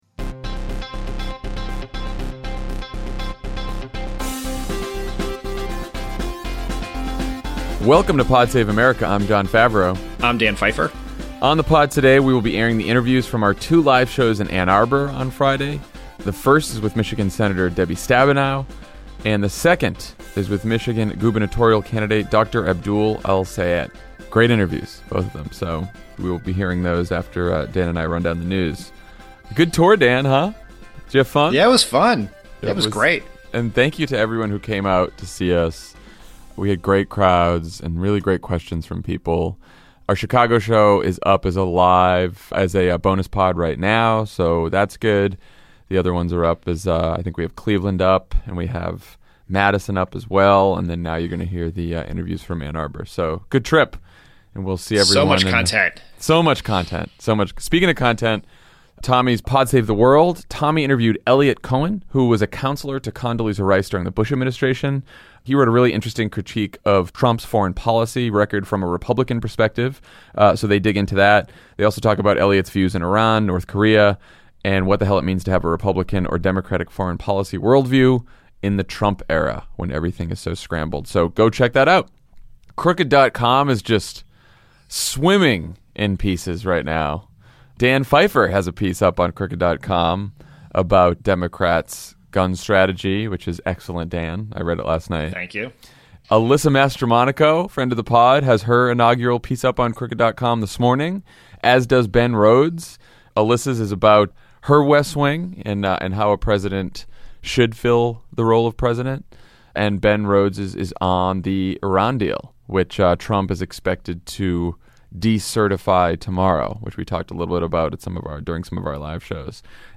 Trump continues to sabotage Obamacare, threatens to pull media licenses, threatens the NFL’s tax exemption, wishes for more nuclear weapons, and yells that he hates everyone in the White House. Then Michigan Senator Debbie Stabenow talks to Ana and Dan in Ann Arbor